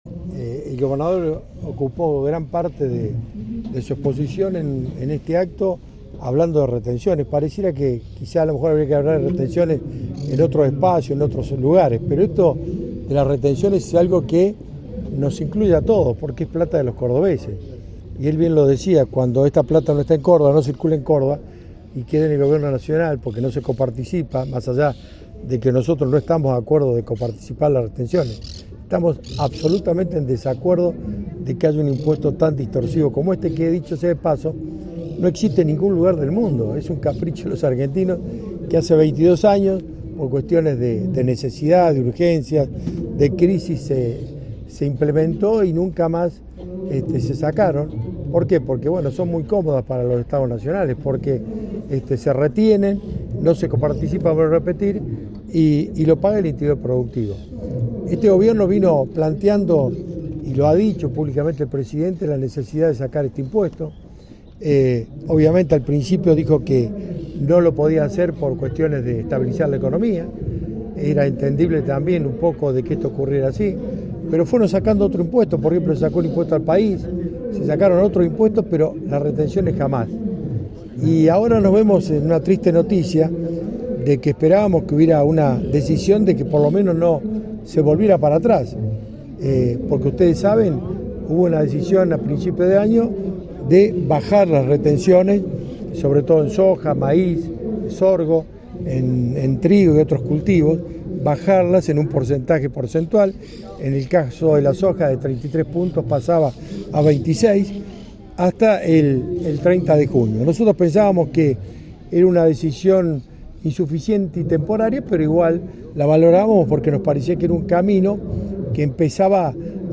En declaraciones formuladas tras el acto encabezado por el gobernador Martín Llaryora, Busso expresó su preocupación por el impacto fiscal y productivo de las retenciones, especialmente en una provincia como Córdoba, donde el peso del sector agroindustrial es determinante.
Ministro-Sergio-Busso-Retenciones.mp3